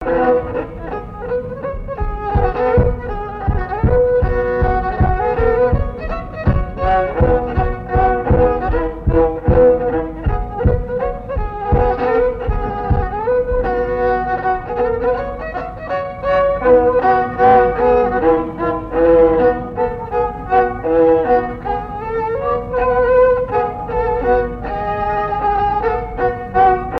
danse : mazurka-valse
Pièce musicale inédite